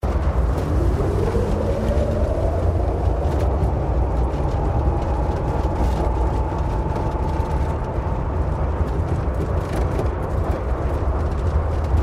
Tempestade
som-de-tempestade.MP3